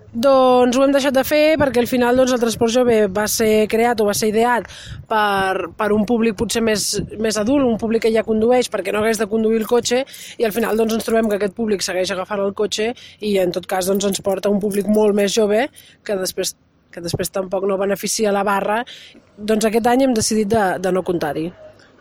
ÀUDIO: Erika Soriano, regidora de joventut, explica els motius de la renúncia